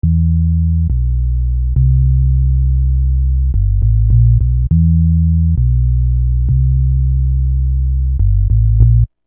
Bass 21.wav